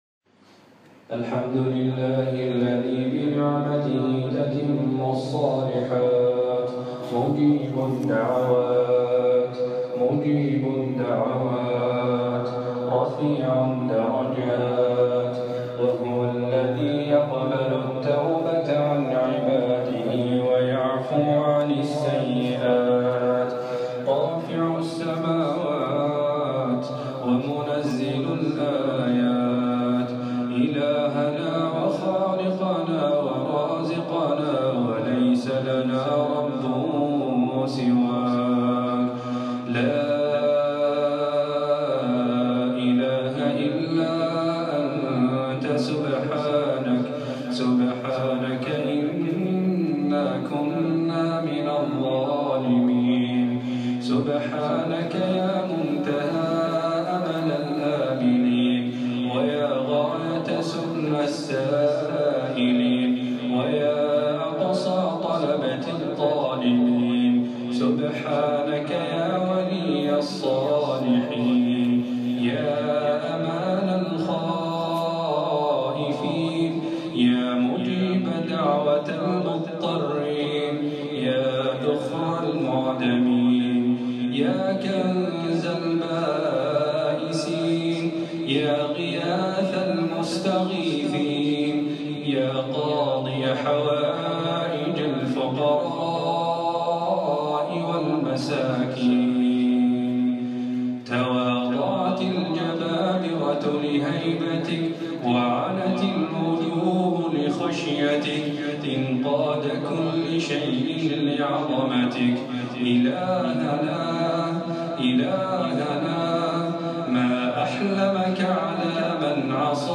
أدعية وأذكار